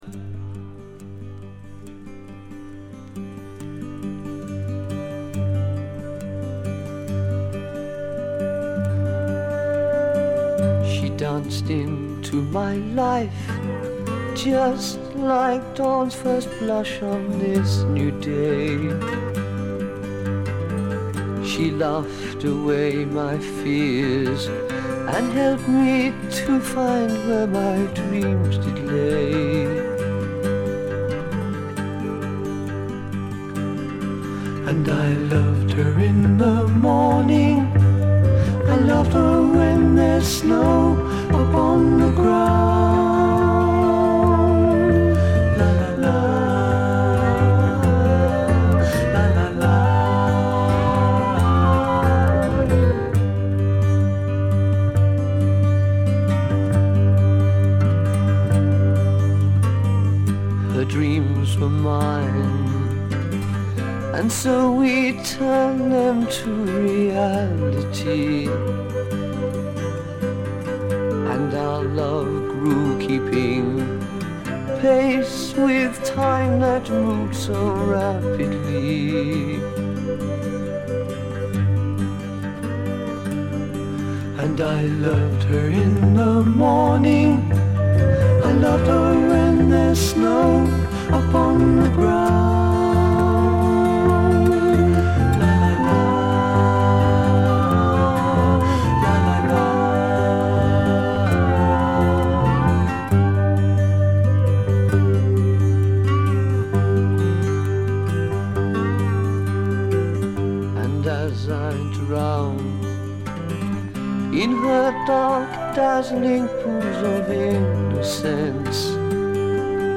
これ以外はわずかなノイズ感のみ。
いまさらいうまでもないピュアで美しい英国製ドリーミーフォークの逸品です。
ドリーミー・フォーク名盤。
試聴曲は現品からの取り込み音源です。
Guitar, 12-String Acoustic Guitar, Vocals